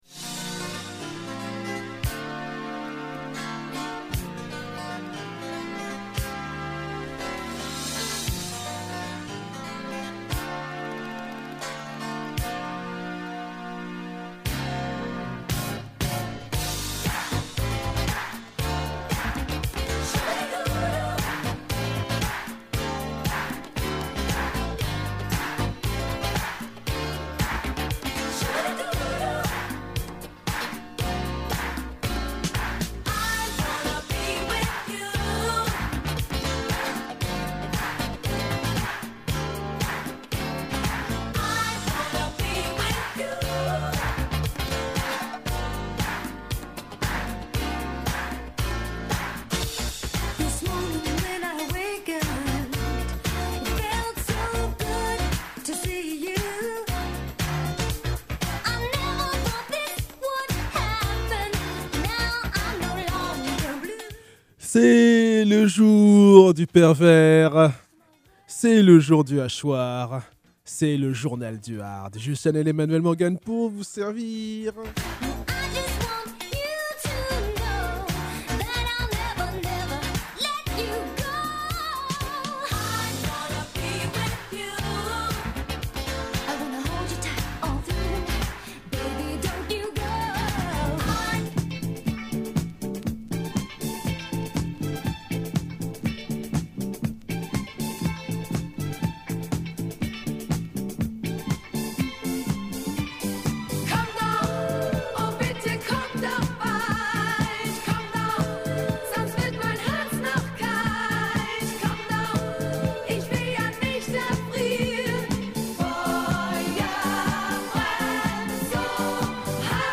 Oui c’est Le Journal Du Hard , nouvel épisode de la saga, Oi contre punk hardcore (et affiliés)!